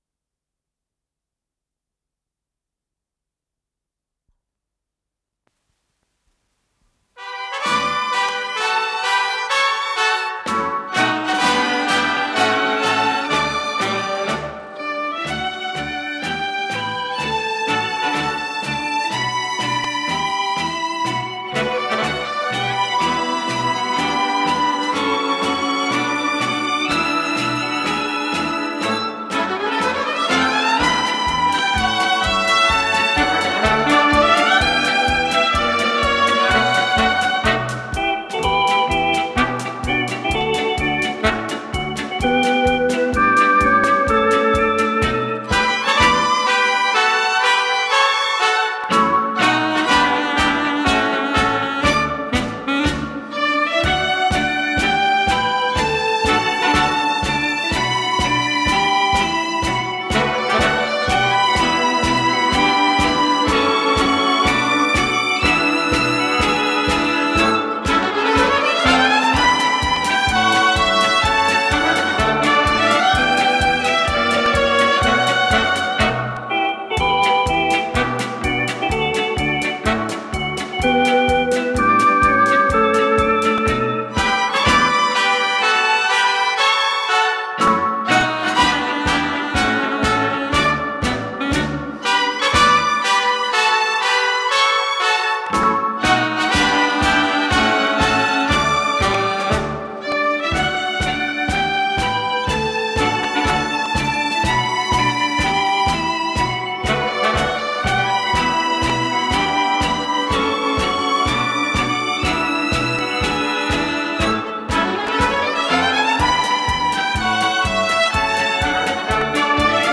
с профессиональной магнитной ленты
ПодзаголовокЗаставка
ВариантДубль моно